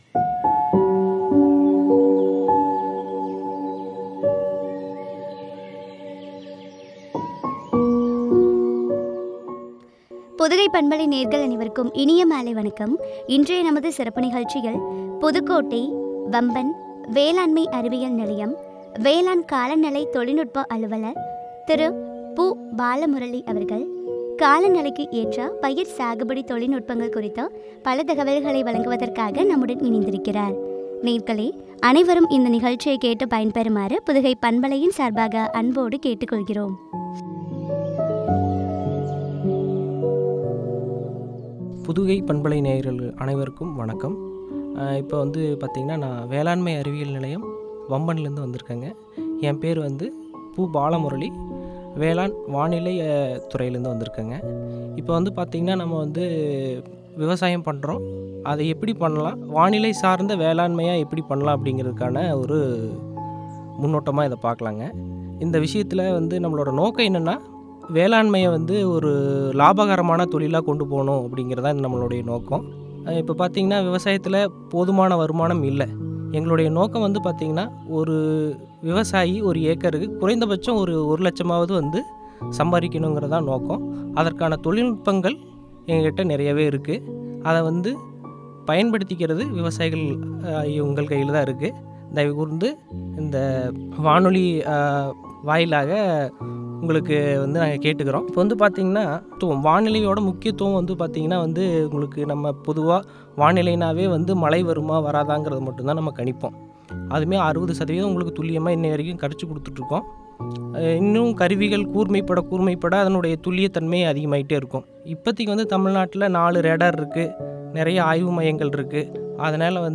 காலநிலைக்கேற்ற பயிர் சாகுபடி தொழில்நுட்பங்கள் பற்றிய உரையாடல்.